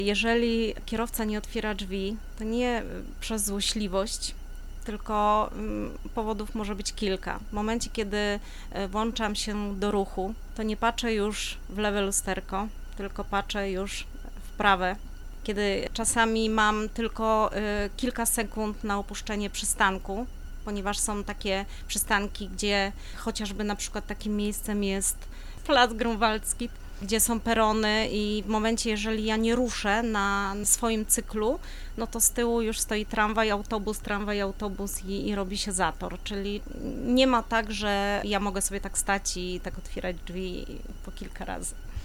Radio Rodzina odwiedziło zajezdnię autobusową przy ul. Obornickiej i porozmawiało o tych i innych tematach z pracownikami MPK Wrocław.